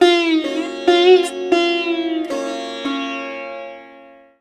SITAR LINE44.wav